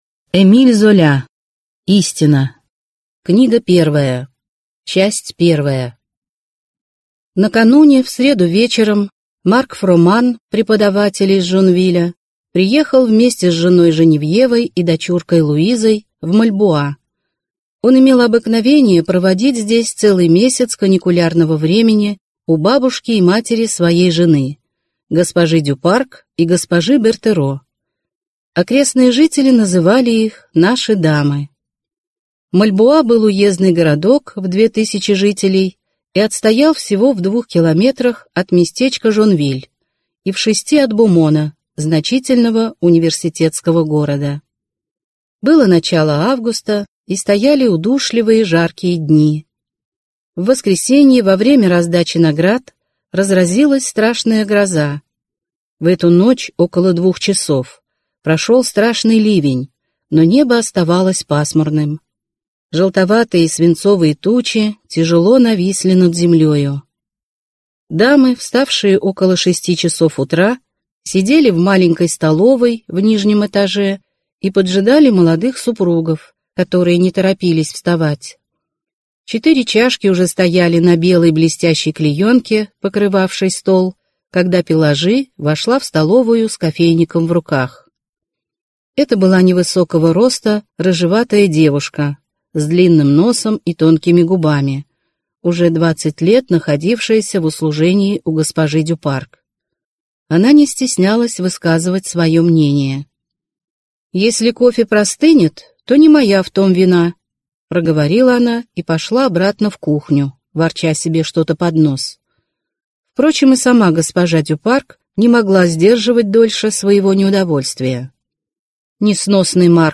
Аудиокнига Истина | Библиотека аудиокниг